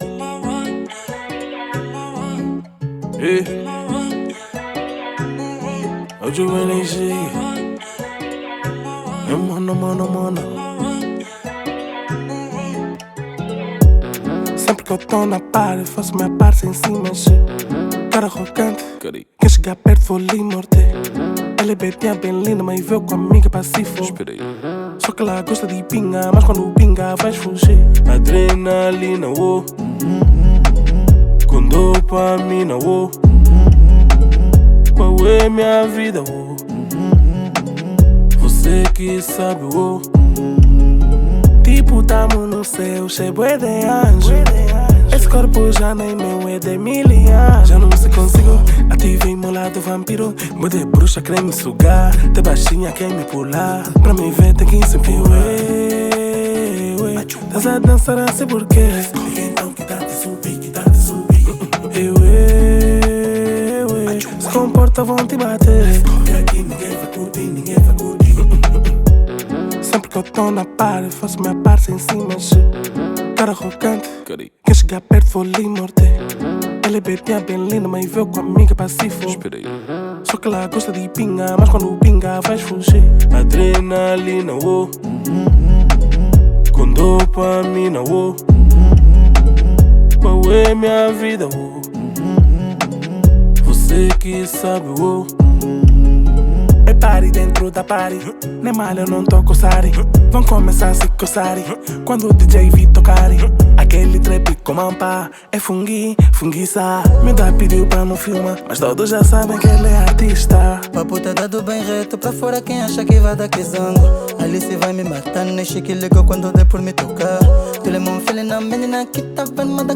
NacionalRap Angolano